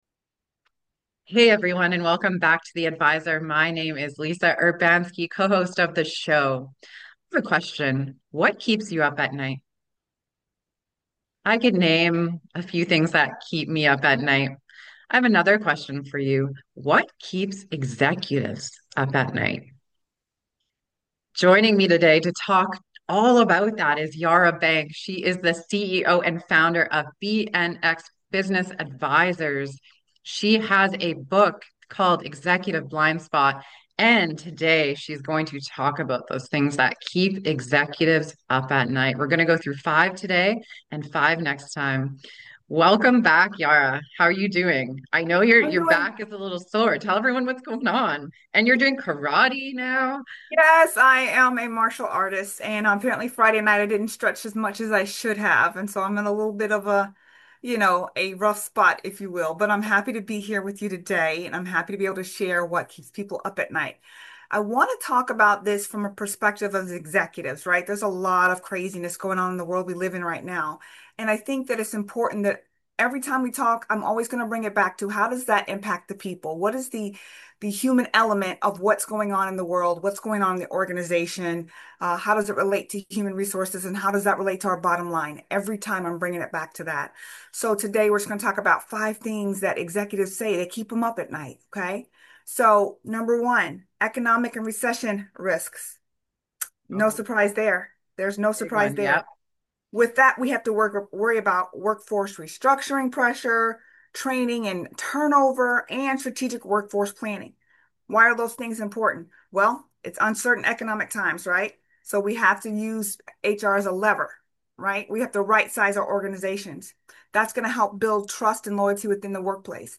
Love insightful and life-changing interviews?